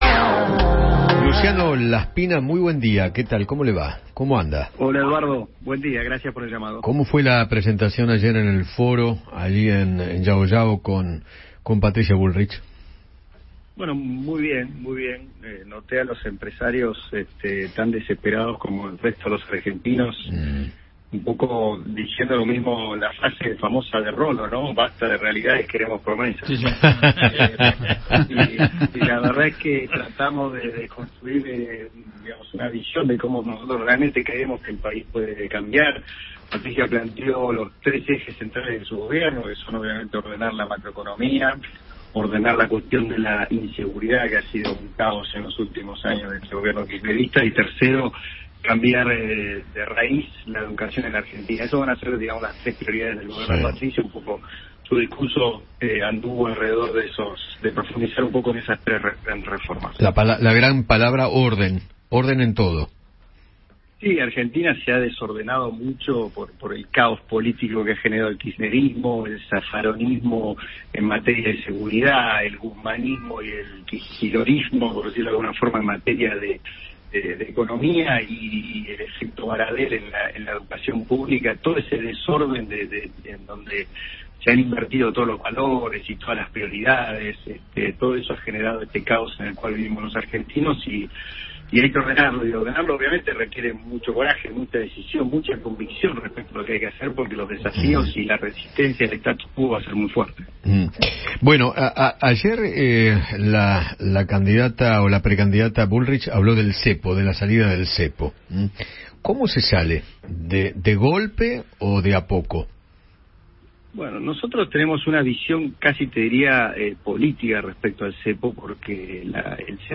Luciano Laspina, diputado y parte del equipo de Patricia Bullrich, dialogó con Eduardo Feinmann sobre el Foro de Llao Llao y se refirió al salto cambiario de los últimos días.